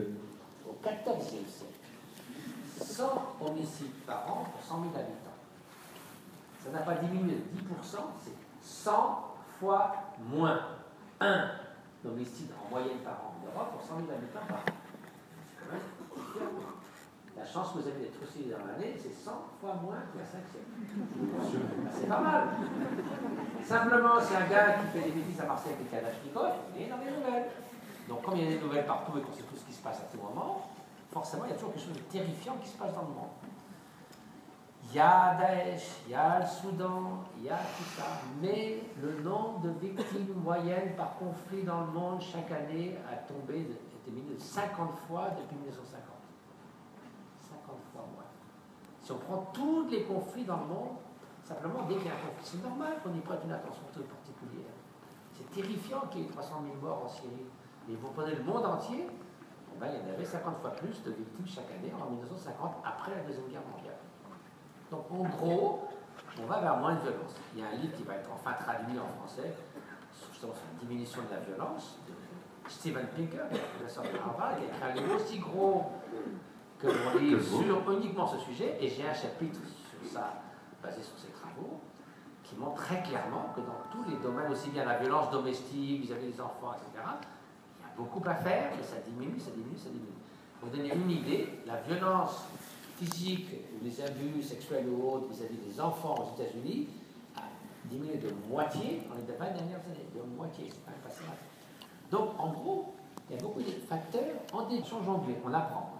Vendredi 6 janvier, Matthieu Ricard était présent pour une rencontre gratuite exceptionnelle à l’Hôtel du Département (Orléans).
Les auditeurs sont assis dans le hall du Conseil Départemental vendredi après midi.